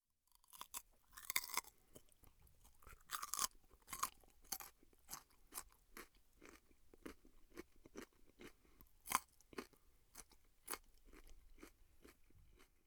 Die knackt noch viel lauter als der Apfel!
Abbeissen_Moehre.mp3.mp3